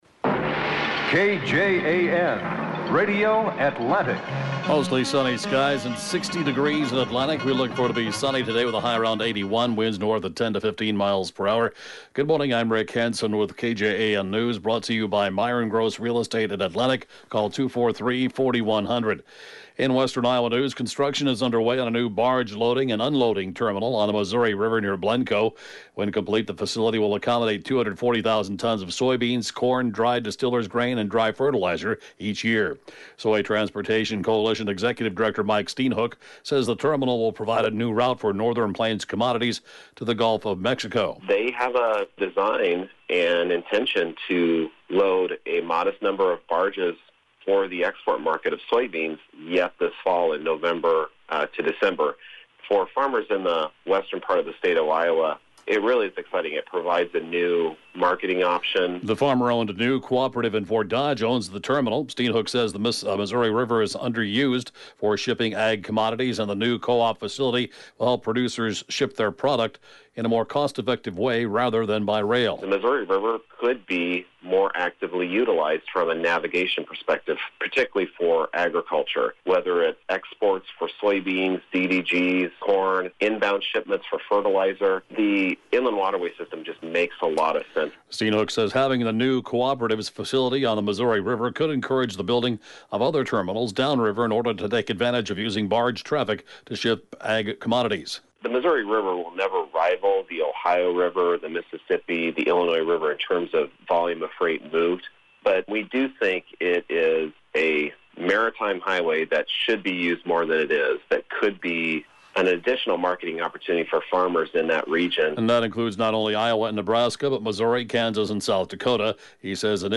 (Podcast) KJAN 8-a.m. News, 9/16/20